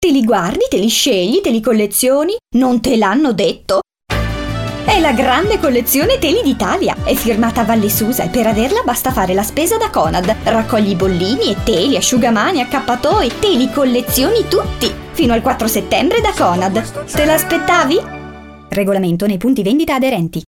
Kein Dialekt
Sprechprobe: Werbung (Muttersprache):
I am a speaker and dubber from Milan with a fresh, professional, extremely versatile kind of voice and, of course, perfect diction.